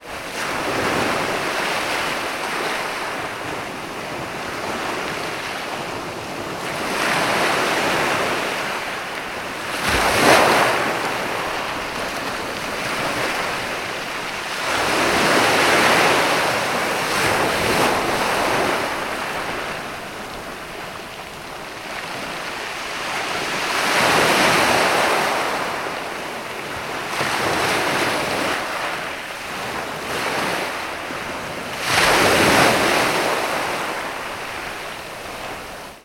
Шум прибоя с легким звуком океанских волн